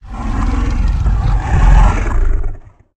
chimera_idle_1.ogg